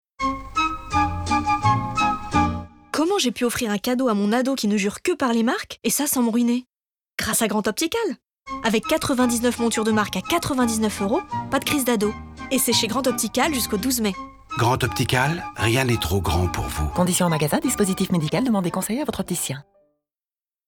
Voix off